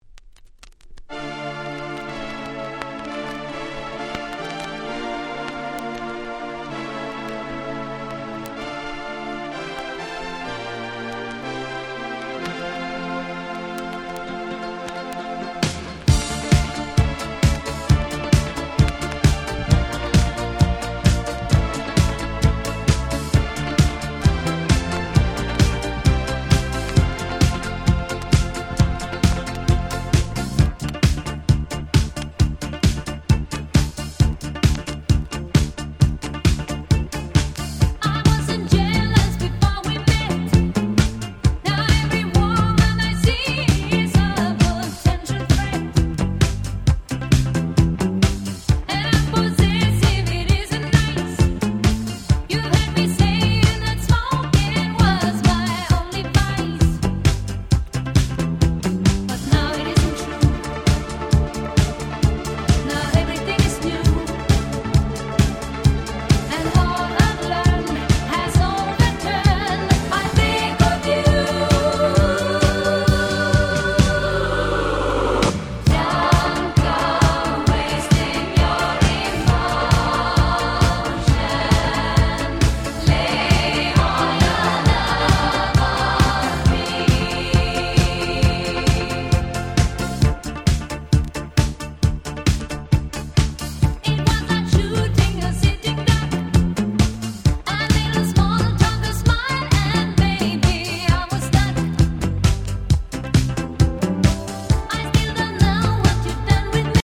アバディスコダンクラ Boogie ブギーダンスクラシック Soul ソウル